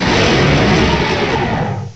cry_not_kommo_o.aif